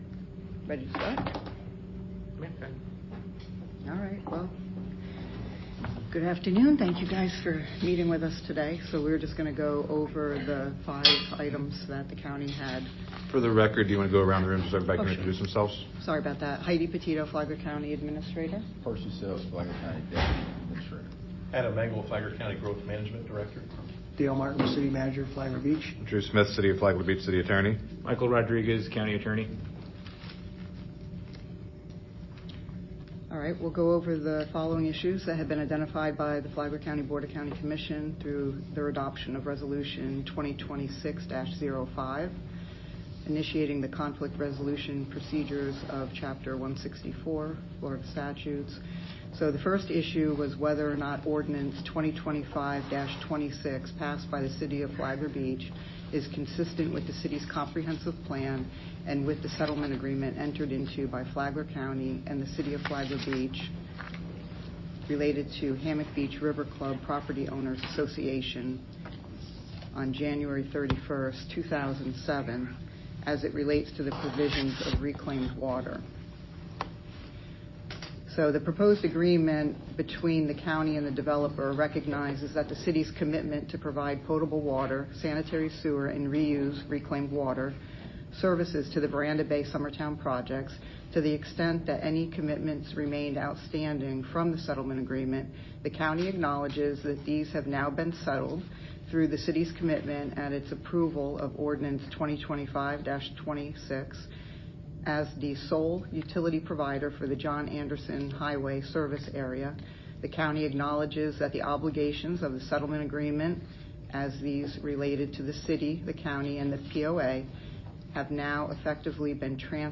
The resolutions were attained in a 70-minute negotiating session between the two sides Friday at the Government Services Building, making further steps toward litigation very unlikely.
negotiating-session.mp3